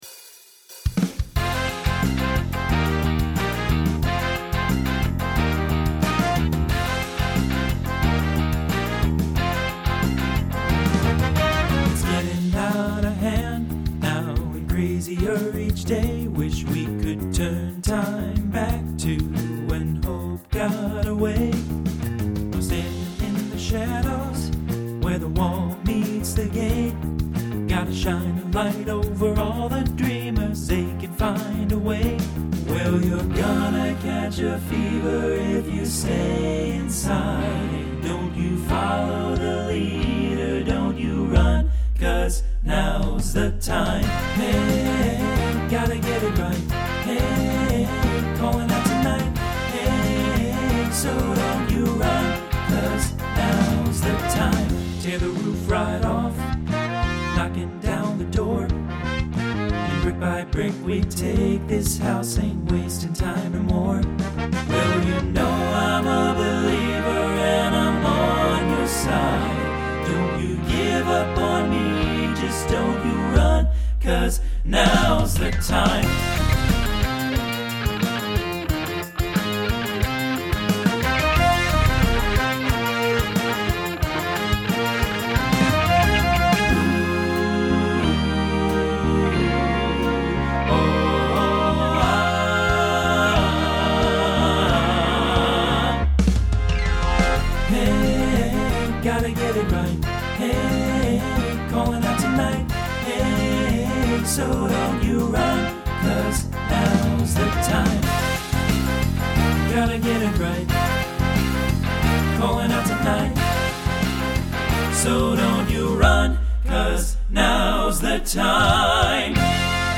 Pop/Dance , Rock
Transition Voicing TTB